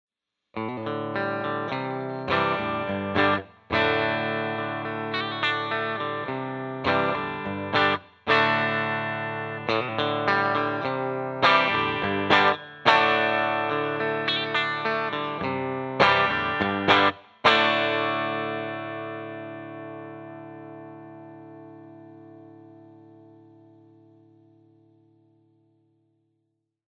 69T (1969 Tele lead type) alone
69T  bridge.mp3